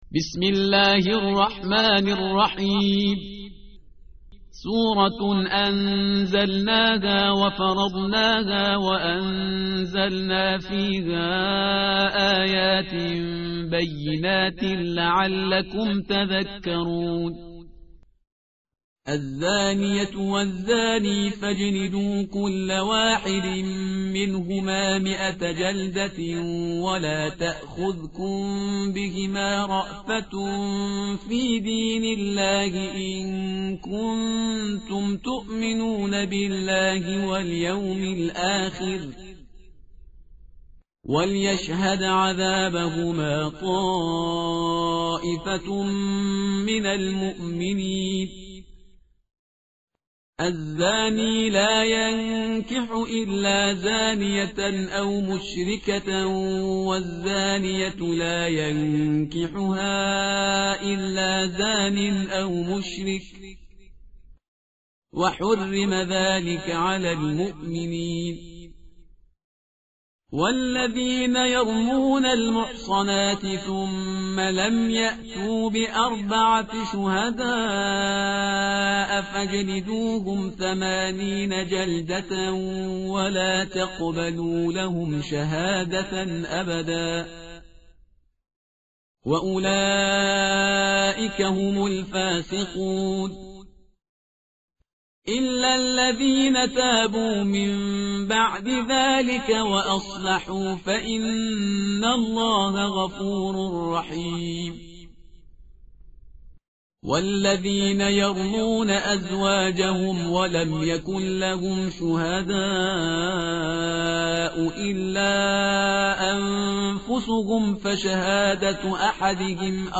متن قرآن همراه باتلاوت قرآن و ترجمه
tartil_parhizgar_page_350.mp3